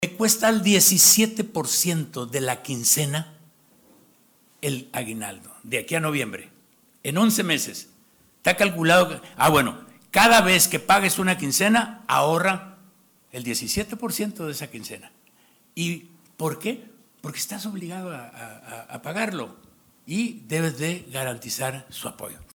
Culiacán, Sinaloa, a 05 de Enero de 2026.- A través de estrategias de recaudación, una ordenada planeación de gastos, firma de convenios para cubrir obligaciones en pagos mensuales, entre otras acciones, Gobierno del Estado está avanzando en la regularización de adeudos derivados de anteriores administraciones y de apoyos requeridos por diversas instituciones como la Universidad Autónoma de Sinaloa, precisó este lunes el Gobernador Rubén Rocha Moya en su Conferencia Semanera.